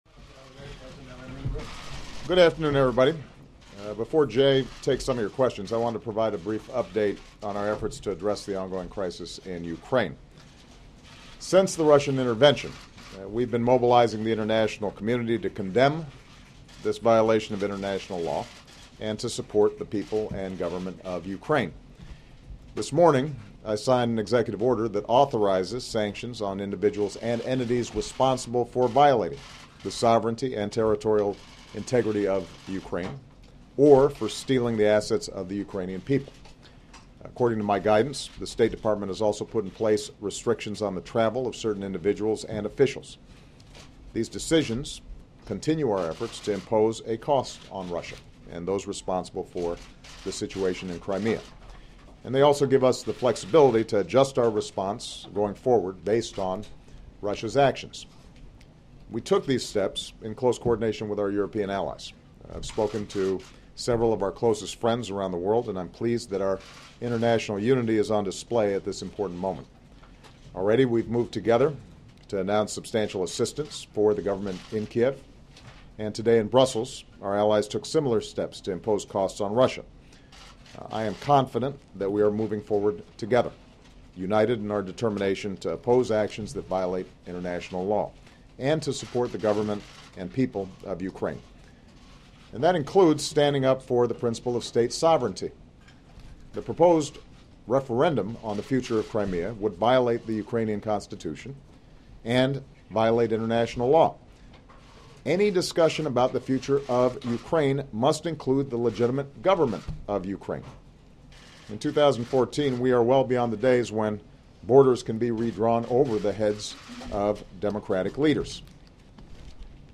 U.S. President Barack Obama delivers a statement regarding the ongoing crisis in Ukraine